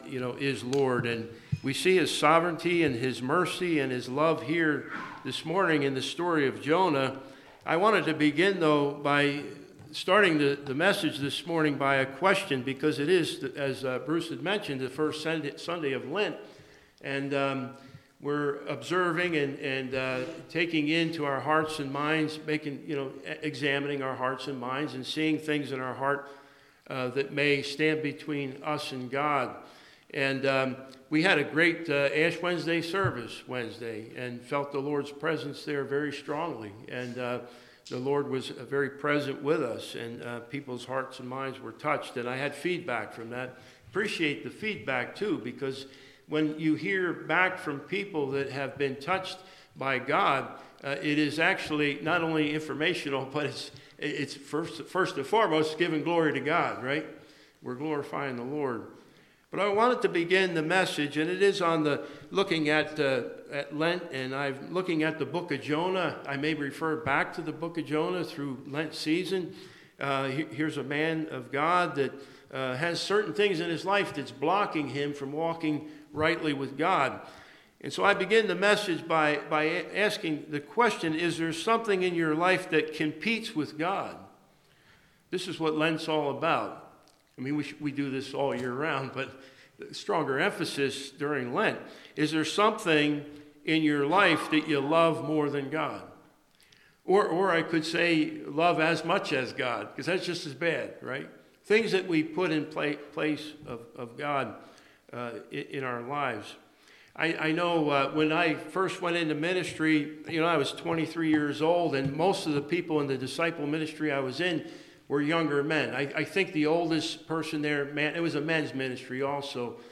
Worship Service – March 9,2025 « Franklin Hill Presbyterian Church